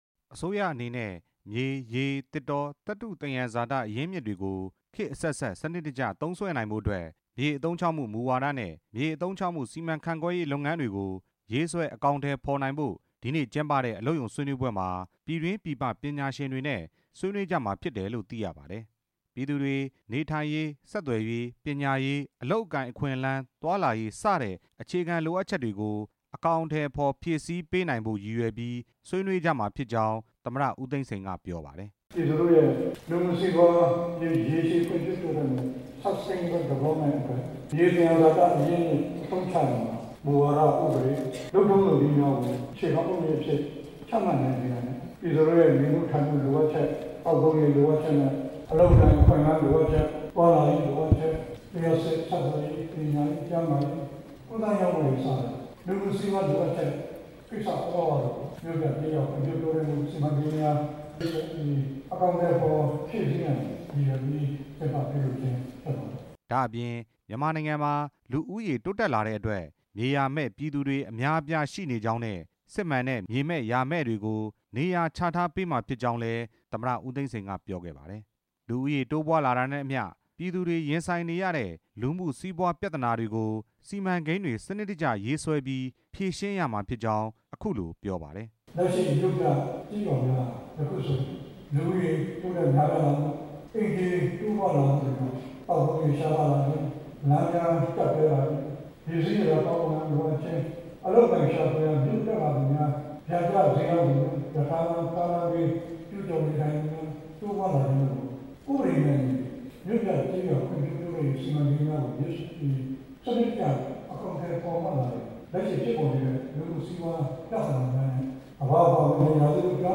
နေပြည်တော် မြန်မာအပြည်ပြည်ဆိုင်ရာကွန်ဗင်းရှင်း စင်တာမှာ ဒီနေ့ ကျင်းပတဲ့ မြို့ပြကျေးရွာ ဖွံ့ဖြိုးရေး စီမံကိန်းများအတွက် မြေအရင်းအမြစ် စီမံခန့်ခွဲရေး ဆိုင်ရာ အလုပ်ရုံဆွေးနွေးပွဲမှာ နိုင်ငံတော်သမ္မတ ဦးသိန်းစိန်က ထည့်သွင်း ပြောကြားခဲ့ တာဖြစ်ပါတယ်။